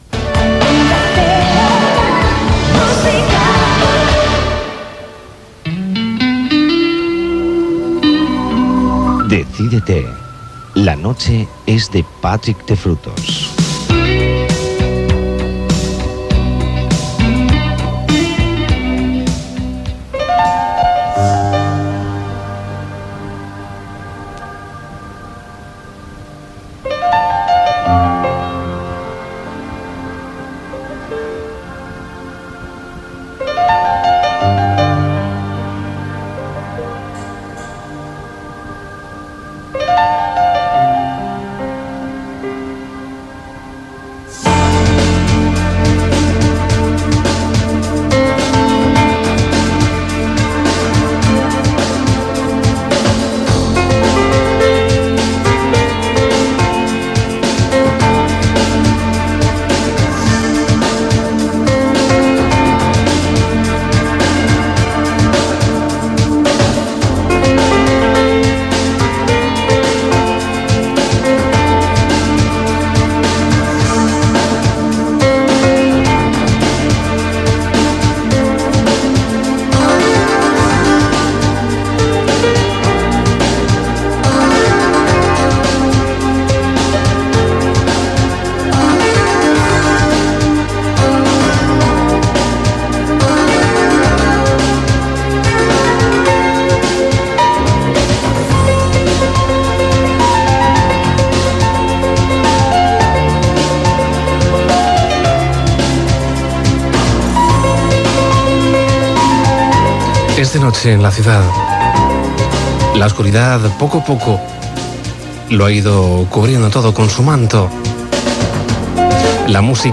Indicatiu de l'emissora i del programa, salutaciò inicial amb la data, la identificació de l'emissora i l'hora i tema musical
Musical
FM